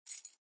钥匙扣 " 钥匙扣11
描述：录音设备：Sony PCMM10Format：24 bit / 44.1 KHz
Tag: 样品 录音 FOLE Y